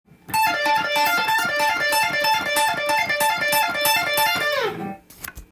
エレキギターで弾ける【ランディーローズフレーズ集】tab譜
フレーズ集は、全てDm　keyで使用できるものになっています。
④のフレーズがロックやメタルの常套３和音フレーズです。
Dmの３和音の弾いているだけなんですが、６連符なので